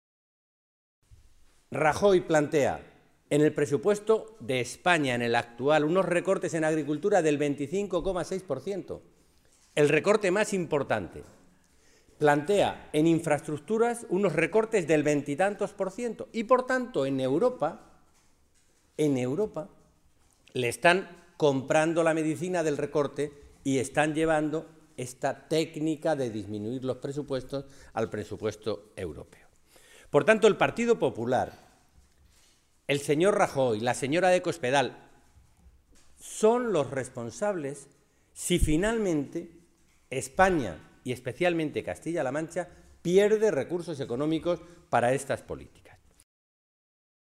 Alejandro Alonso, diputado nacional del PSOE de Castilla-La Mancha
Cortes de audio de la rueda de prensa